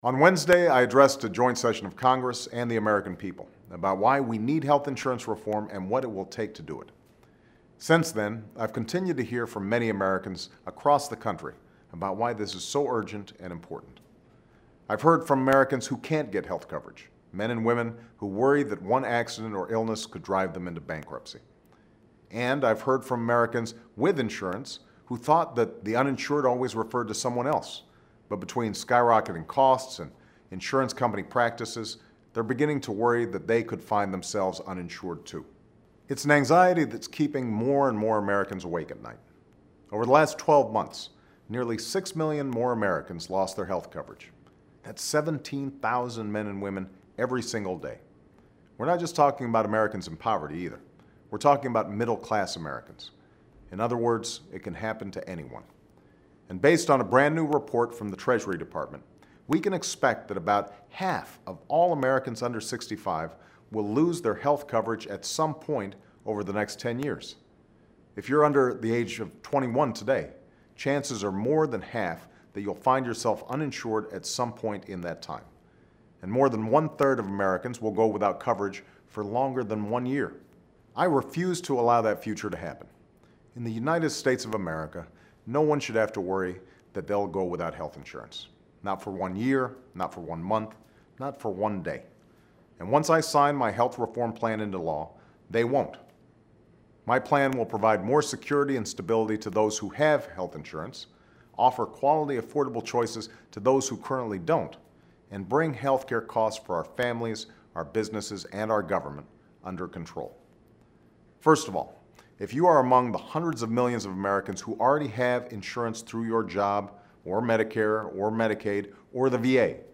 Weekly Address: Losing Insurance Can Happen to Anybody